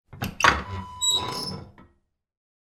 Wood Burning Kitchen Stove Door Open Wav Sound Effect #2
Description: The sound of opening the door of a wood burning kitchen stove
Properties: 48.000 kHz 24-bit Stereo
A beep sound is embedded in the audio preview file but it is not present in the high resolution downloadable wav file.
Keywords: wood, burn, burning, kitchen, stove, oven, fire, metal, hatch, open, opening
wood-burning-kitchen-stove-door-open-preview-2.mp3